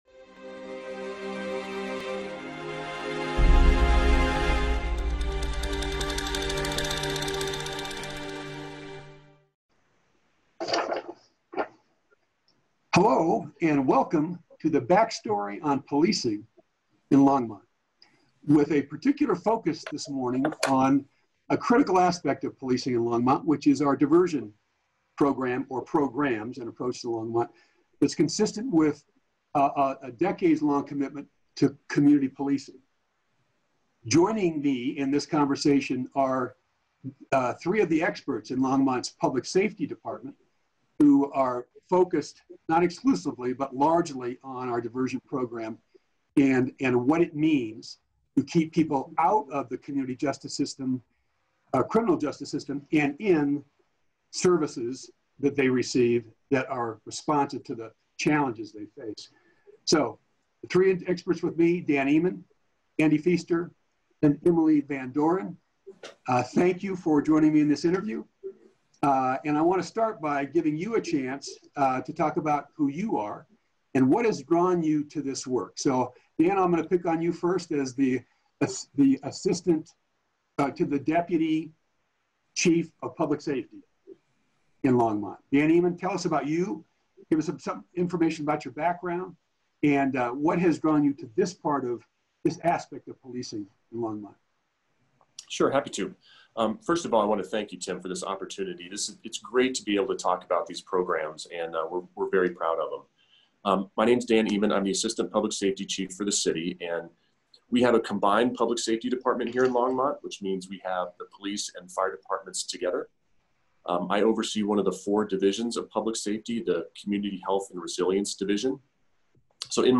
This Backstory includes an interview with three leaders in Longmont's public safety department responsible for diversion efforts to keep people out of the criminal justice system and connect them with social service or health care systems needed for treatment and problem sovling.